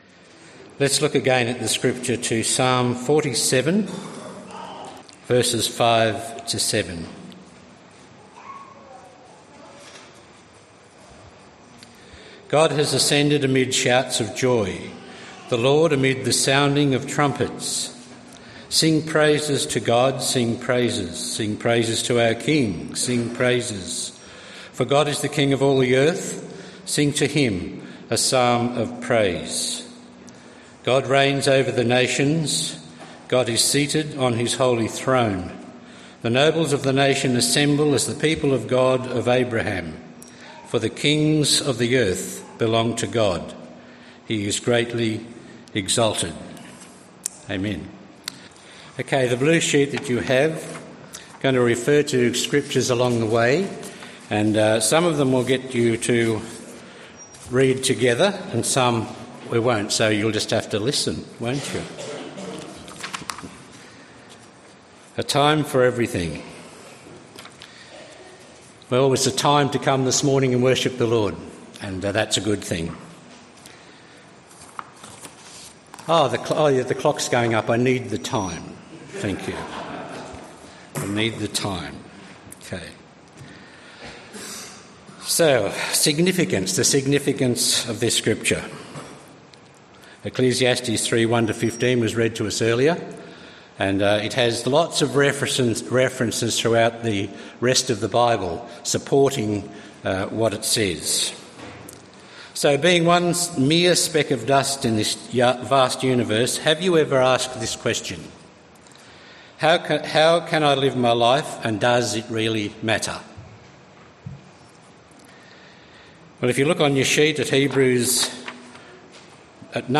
Bible readings and message from the 10AM meeting. The Bible readings were taken from Psalm 47:5-7 and Ecclesiastes 3.